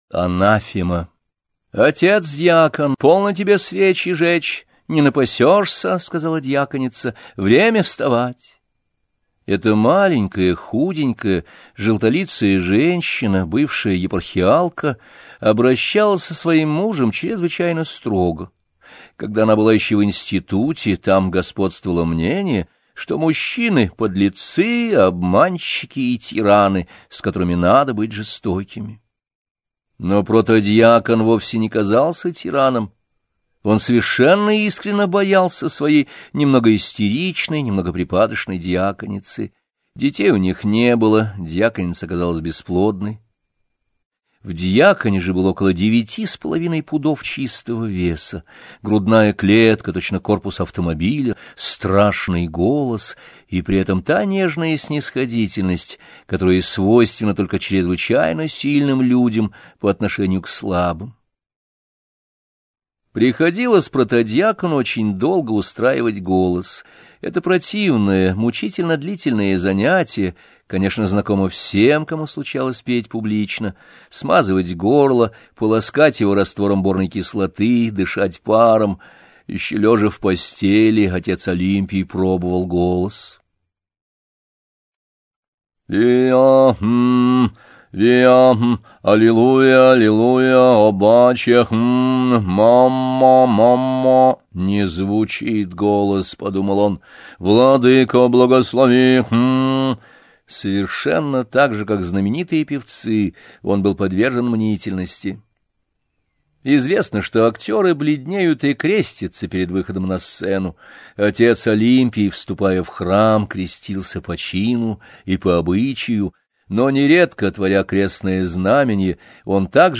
Аудиокнига Гранатовый браслет.